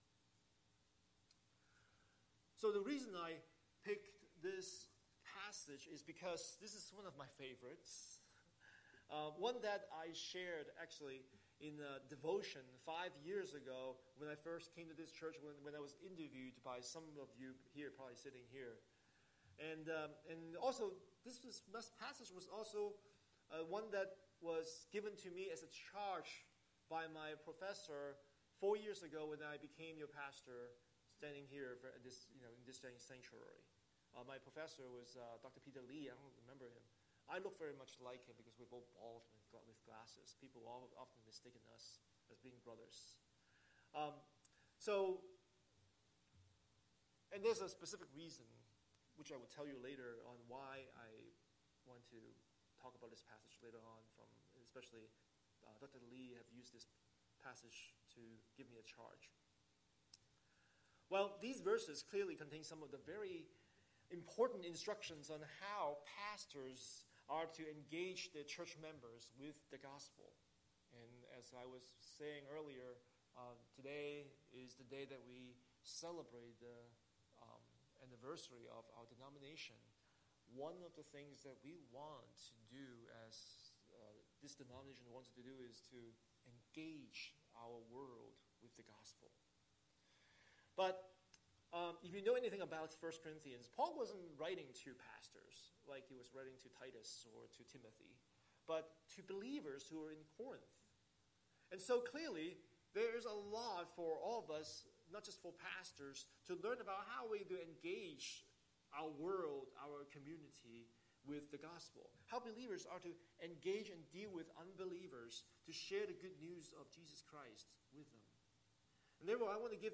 Scripture: Ruth 1:1-5, 4:13-22 Series: Sunday Sermon